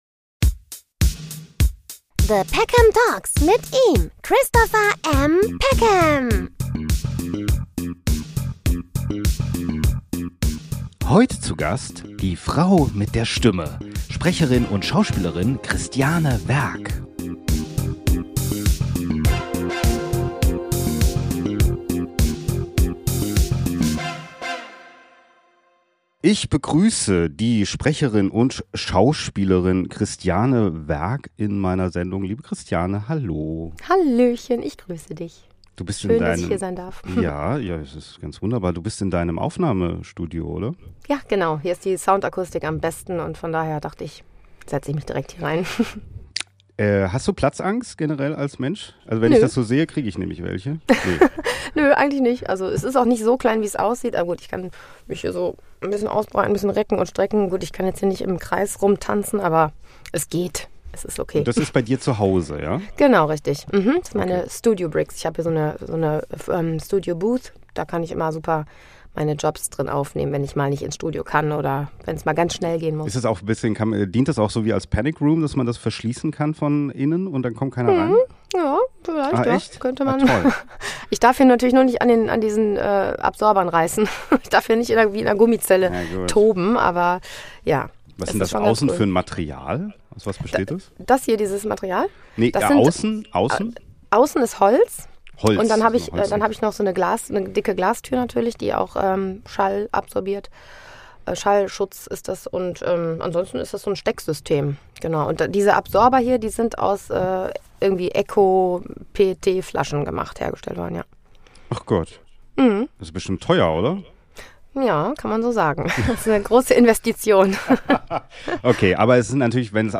Talkshow